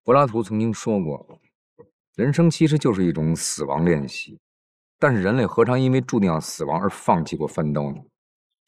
Övertygande manlig AI-röst
Fånga uppmärksamhet med en djup, auktoritativ AI-röst designad för försäljning, motivation och berättande med höga insatser.
Text-till-tal
Auktoritativ ton
Djup resonans
Vår AI genererar en djup, resonant manlig röst som naturligt befaller respekt och uppmärksamhet.